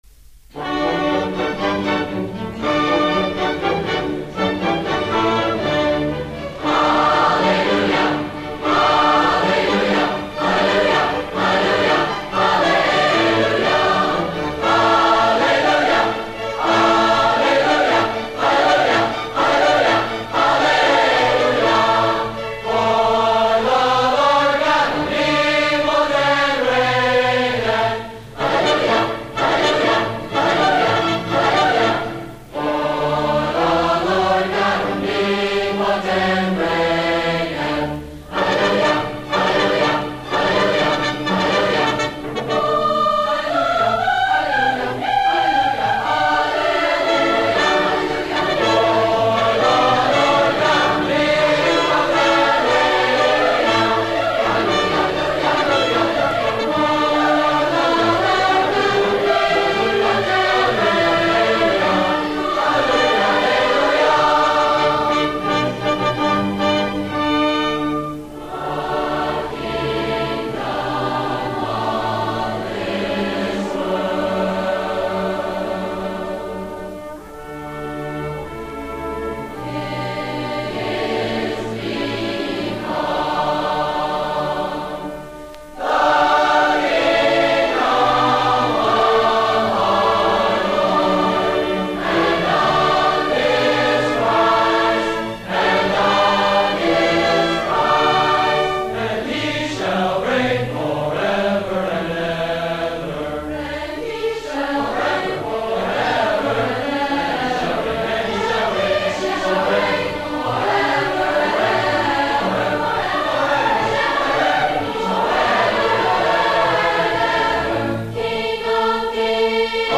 Recordings of public performances of the Music Department
Annual Christmas Concert, 20 December 1965
Hallelujah Chorus (from the Messiah) (G. F. Handel) — The Massed Choirs and Orchestra — 3:31